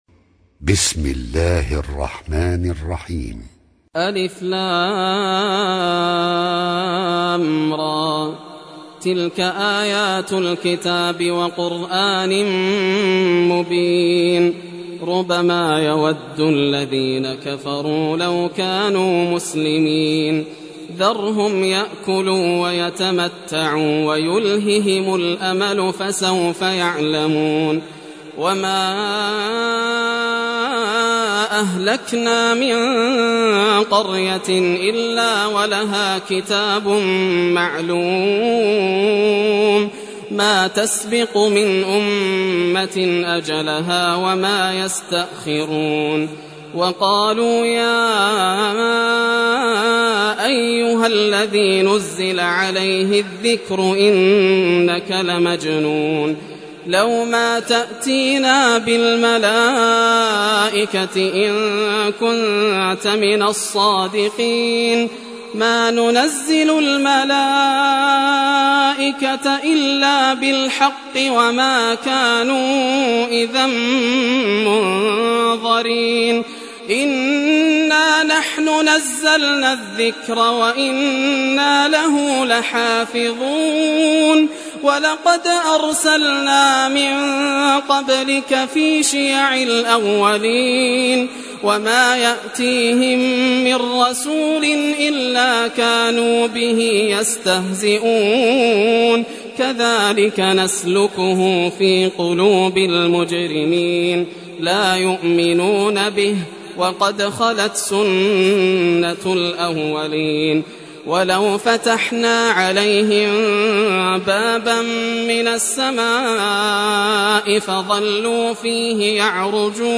Surah Al-Hijr Recitation by Yasser Al Dosari
Surah Hijr, listen or play online mp3 tilawat / recitation in Arabic in the beautiful voice of Sheikh Yasser Al Dosari.